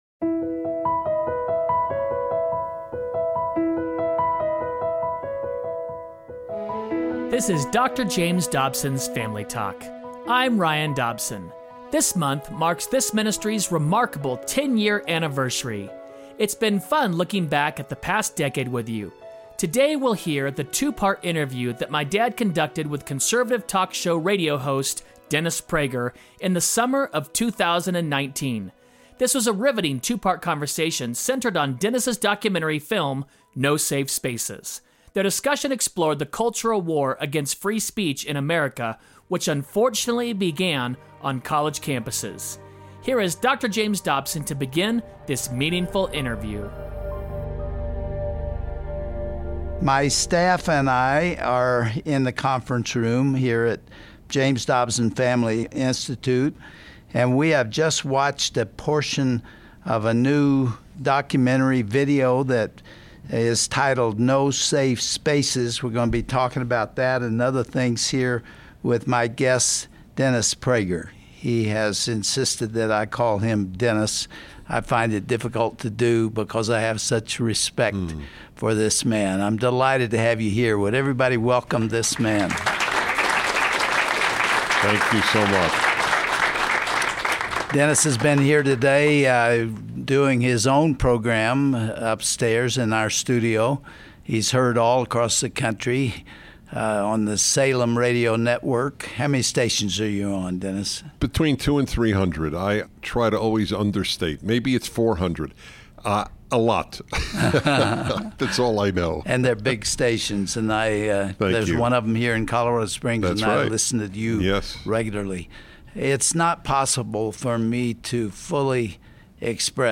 On this Family Talk broadcast, Dr. Dobson talks with popular radio host, Dennis Prager, about Dennis new documentary No Safe Spaces. Hear how free speech is under attack across the nation.